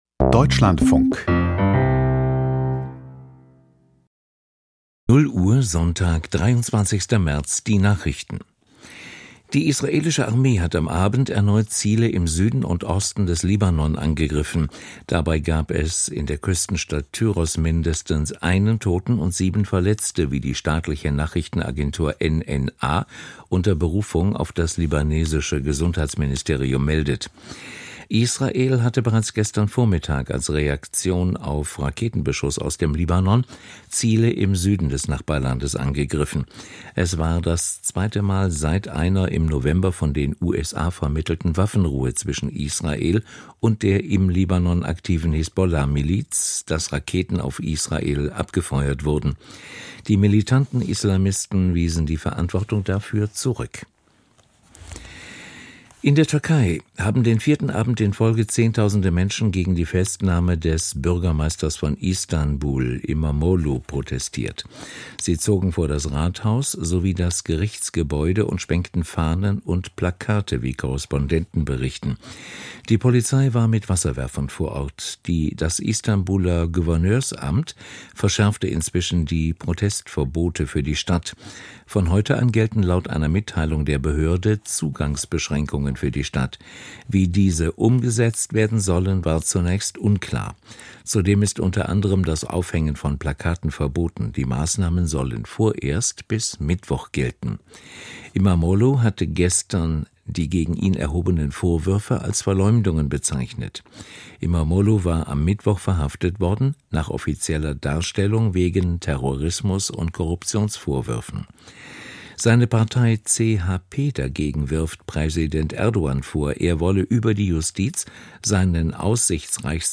Die Deutschlandfunk-Nachrichten vom 23.03.2025, 00:00 Uhr